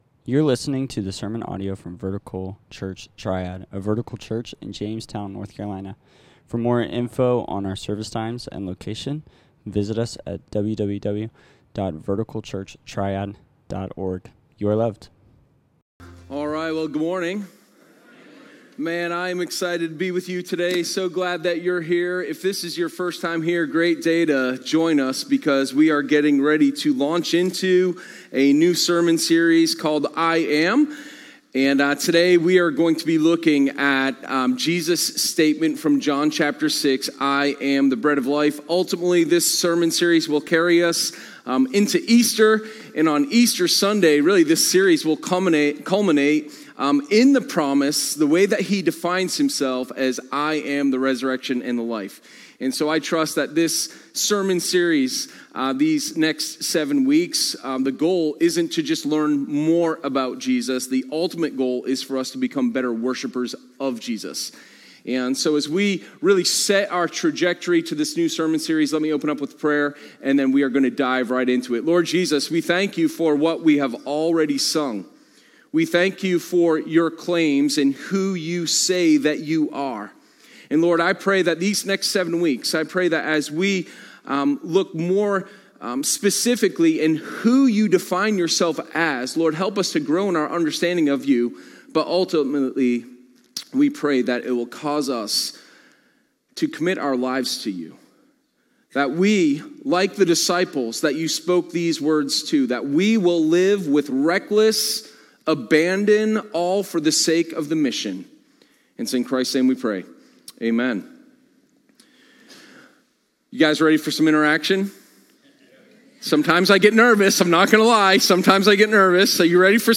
Sermon03_06_I_Am_The_Bread_of_Life.m4a